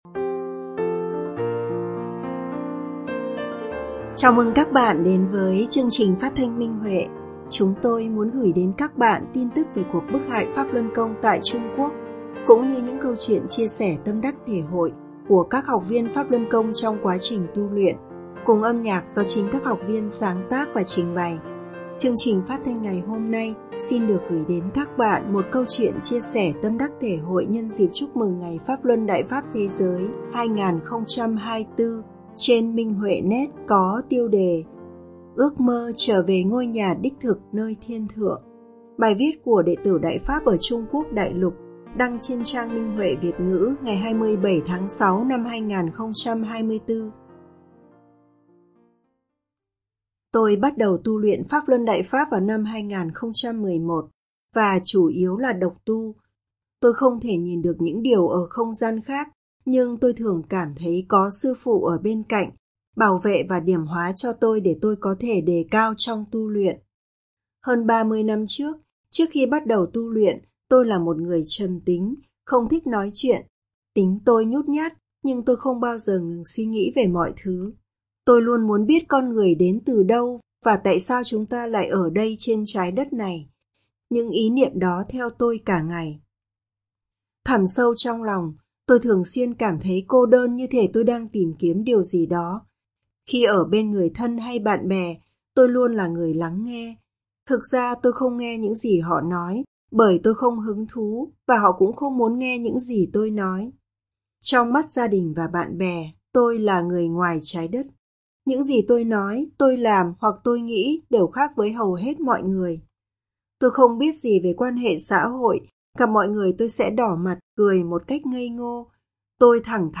Chào mừng các bạn đến với chương trình phát thanh Minh Huệ.